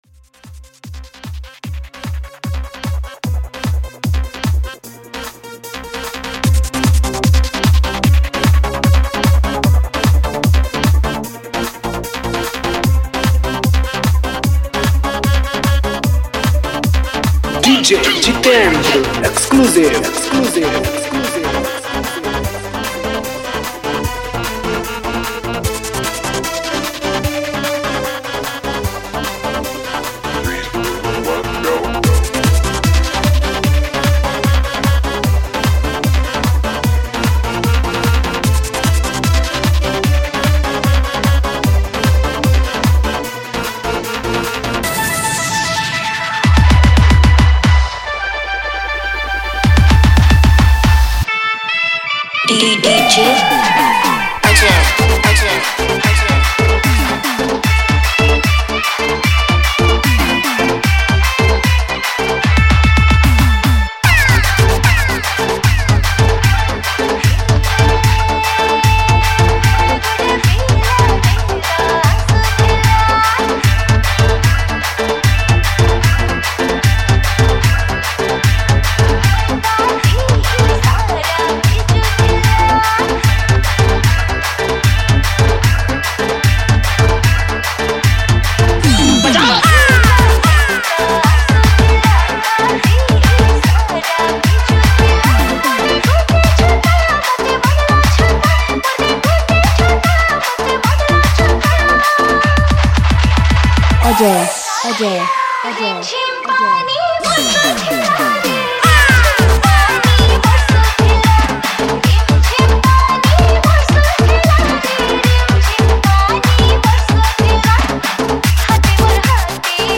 Sambalpuri Dj Song 2024
Category:  Odia New Dj Song 2019